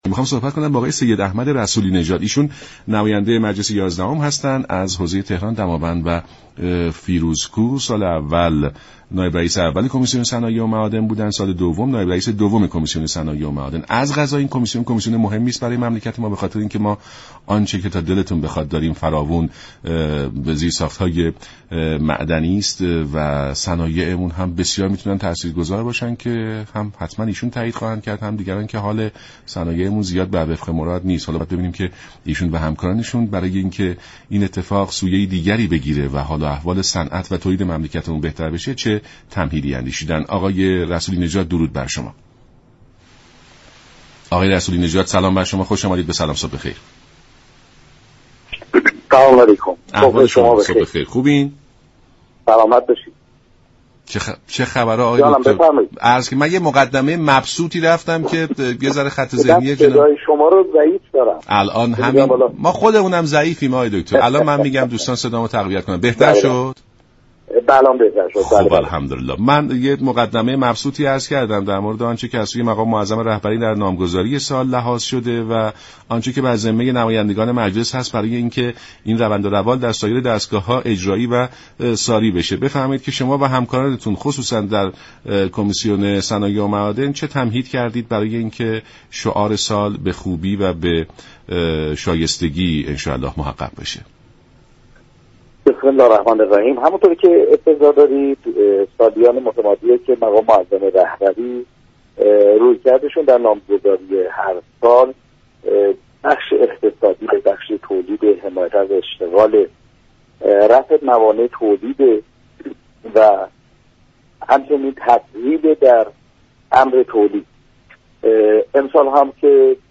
به گزارش شبكه رادیویی ایران، «سید احمد رسولی نژاد» نایب رئیس كمیسیون صنایع و معادن در برنامه «سلام صبح بخیر» رادیو ایران در پاسخ به این پرسش كه مجلس در به تحقق رساندن شعار سال چه تمهیداتی اندیشیده است؟گفت: مجلس با قانونگذاری و نظارت بر روند اجرای درست قانون فعالیت های خود را در این راستا انجام می دهد.